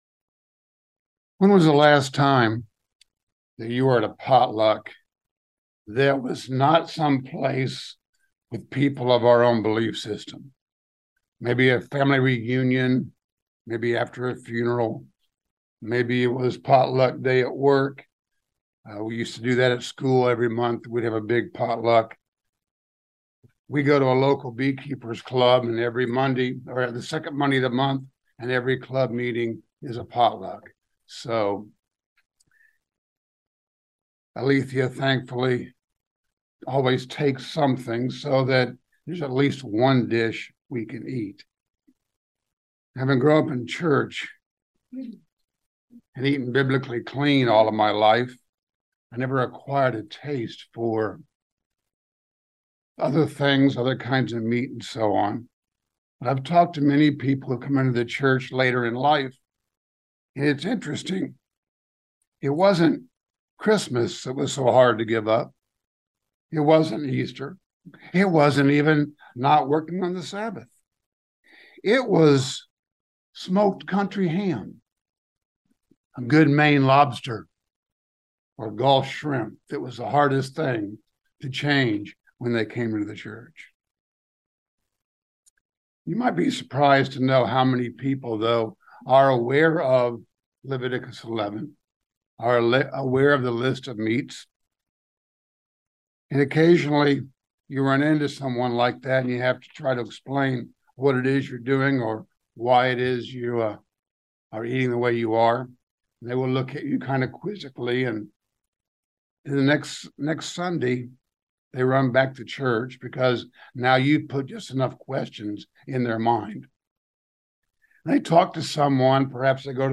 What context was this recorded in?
Given in London, KY